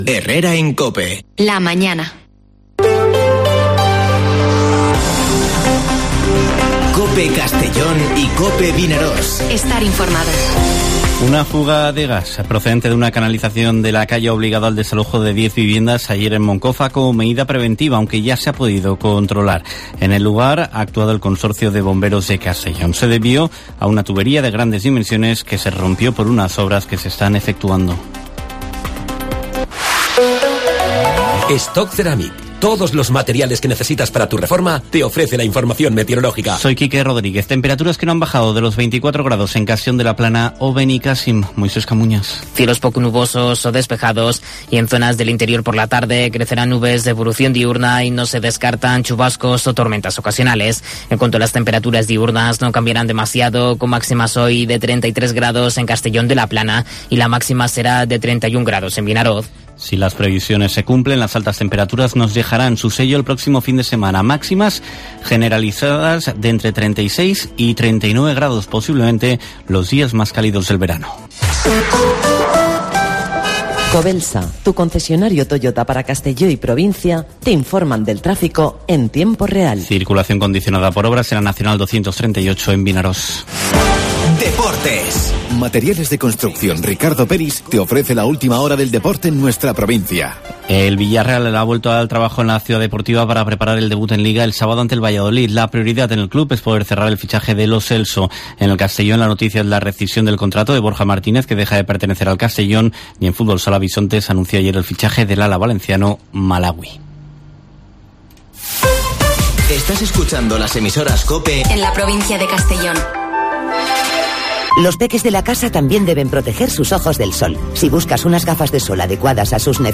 Informativo Herrera en COPE en la provincia de Castellón (09/08/2022)